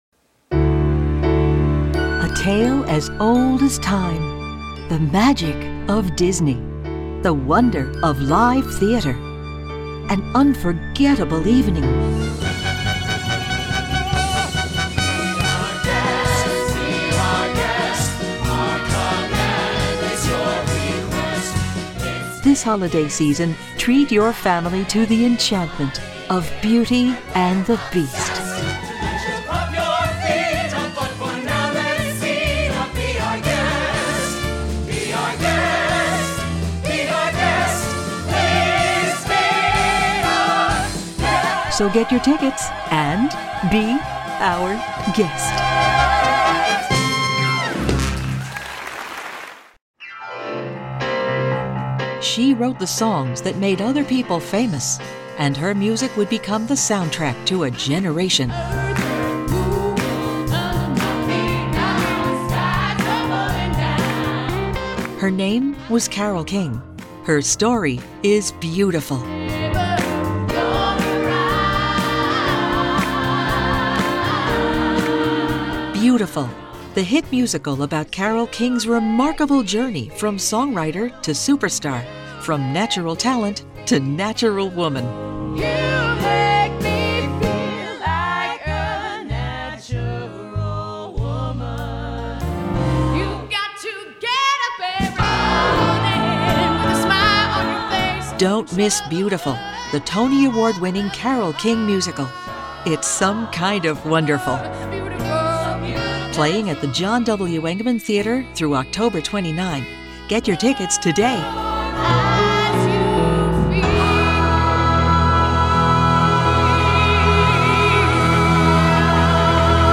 Female
My voice is friendly & warm, engaging & trustworthy, professional, dynamic, bubbly & entertaining.
Radio / TV Imaging
Words that describe my voice are Engaging, Professional, Dynamic.
All our voice actors have professional broadcast quality recording studios.